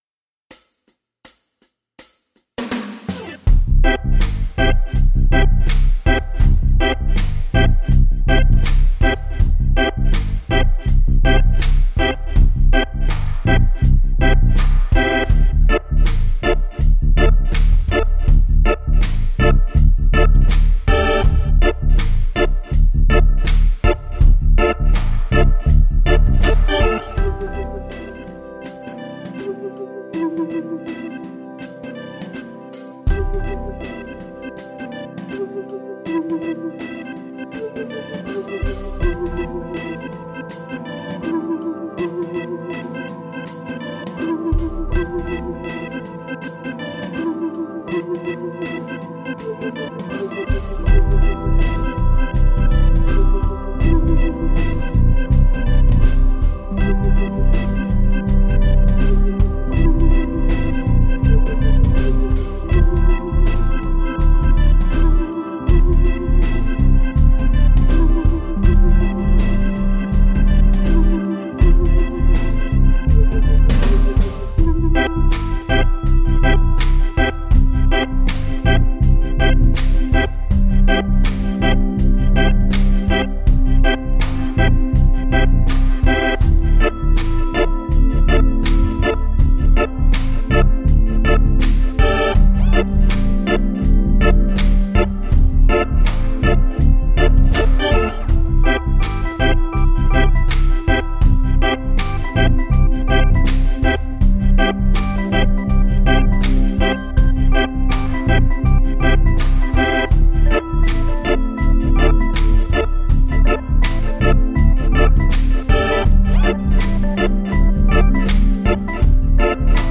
电风琴 344 Audio Electric Organ KONTAKT-音频fun
344 Audio Electric Organ 是一个由 344 Audio 和 Glitchedtones 合作制作的 Kontakt 库，它包含了 2000 多个持续音符的样本，是一种适合爵士、蓝调、摇滚和灵魂等风格的经典乐器。这个库有 10 个主要的风琴音色和 12 个额外的设计音色，非常适合创作电子音乐、电影配乐和各种风格的作品。这个库是在英国纽卡斯尔的一个录音室里，使用了 4 种不同的麦克风来捕捉一个带有旋转扬声器的电风琴的声音，分别是鼓（隔音室）、喇叭（高音驱动器）和立体声房间麦克风。